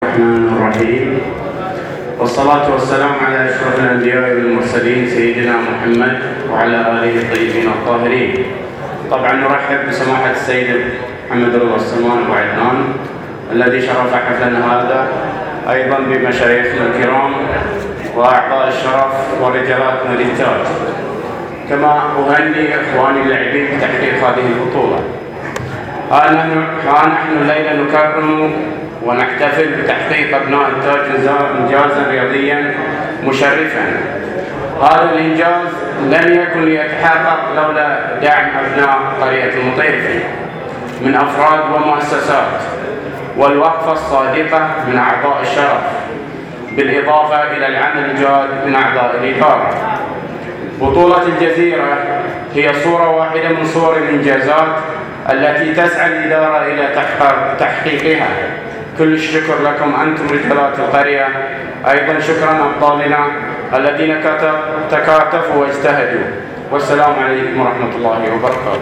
حفل تكريم الداعمين والمساهمين  في تطوير انشطة نادي التاج الرياضي بالمطيرفي
كلمة